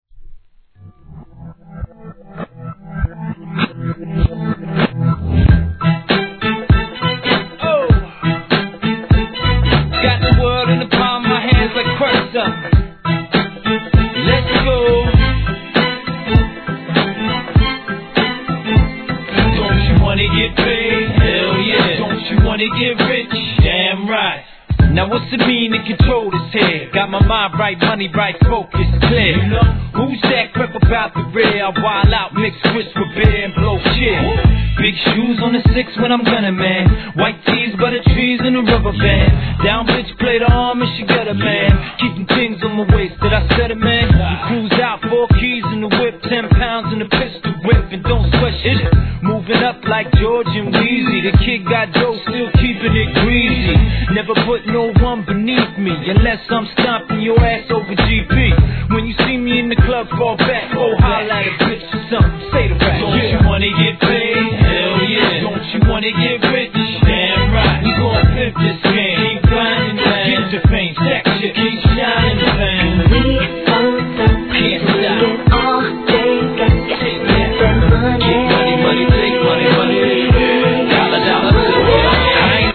HIP HOP/R&B
ノリのいいピアノとフックのストリングスのメロディーライン!!